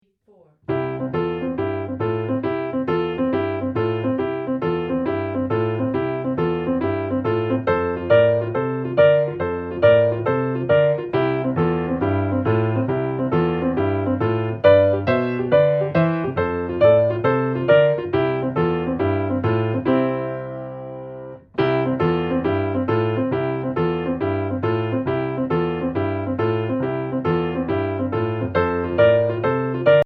Voicing: Piano Method